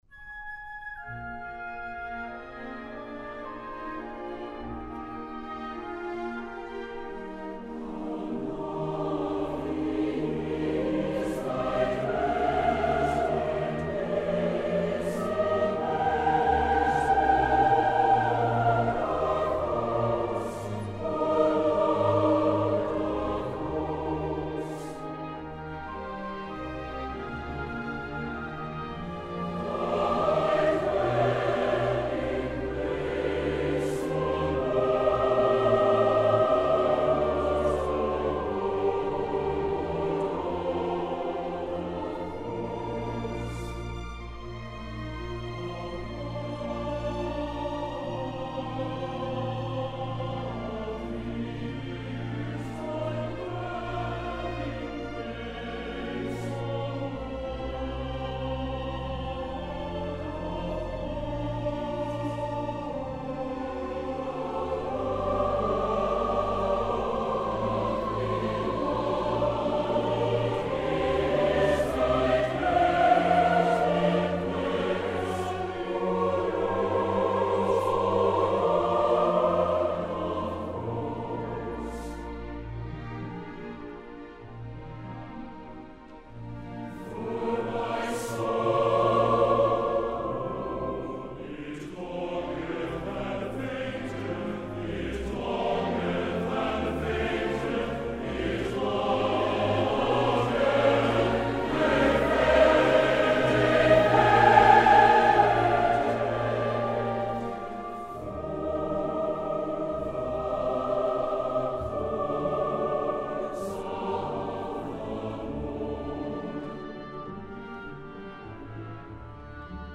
This Sunday (All Saints Sunday, I believe) we are singing at both services Brahms' "How Lovely is Thy Dwelling Place," a beautiful but uber-difficult (at least for a church choir) choral piece from Johannes' German Requiem.
I just wish the soprano part weren't so high so much of the time.